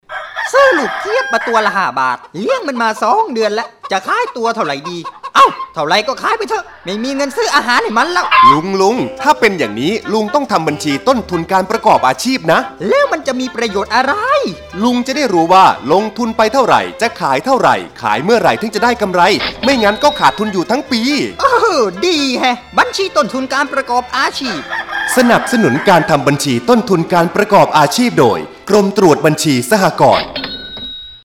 สปอตวิทยุ ส่งเสริมการทำบัญชี แบบที่ 3